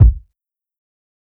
KICK_BROKEN_HEART.wav